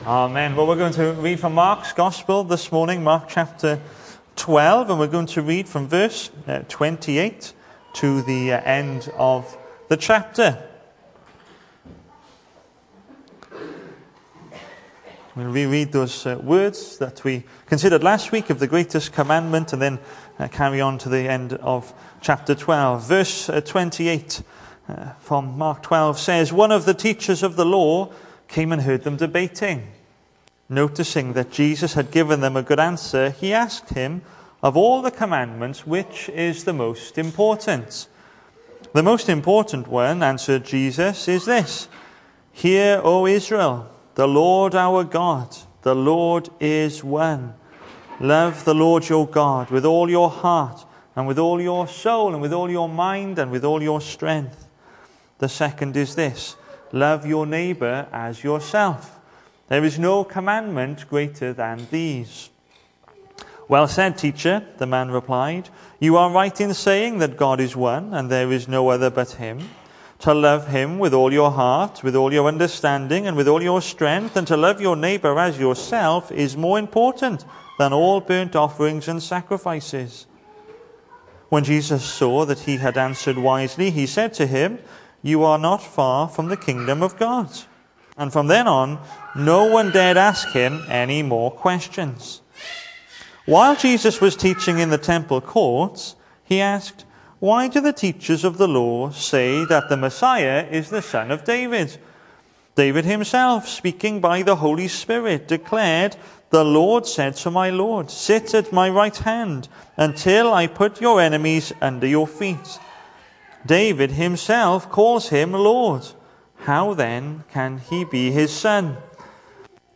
The 31st of August saw us host our Sunday morning service from the church building, with a livestream available via Facebook.
Sermon